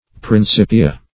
Search Result for " principia" : The Collaborative International Dictionary of English v.0.48: Principia \Prin*cip"i*a\, n. pl.